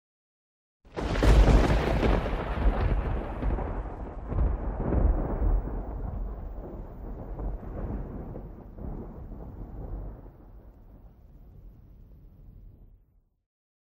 Download Cinematic Thunder sound effect for free.
Cinematic Thunder